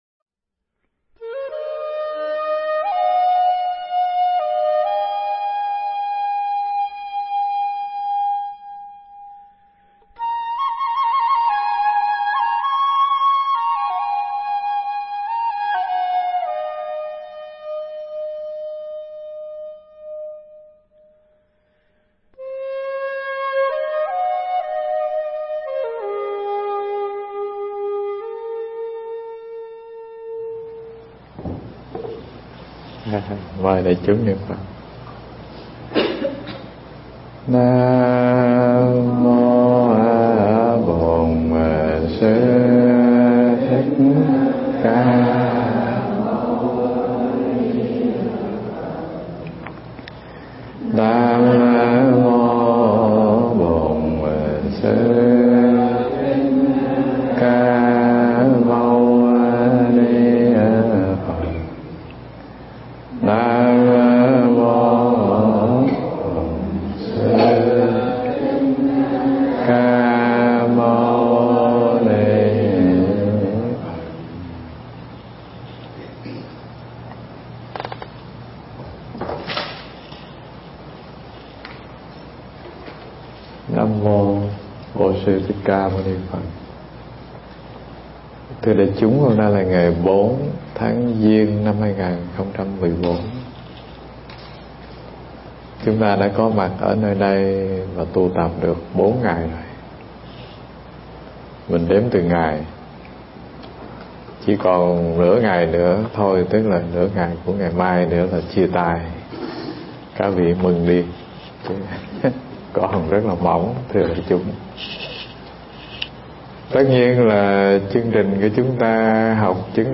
Nghe Mp3 thuyết pháp Chứng Đạo Ca 03 Chứng Thật Tướng